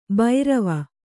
♪ bairava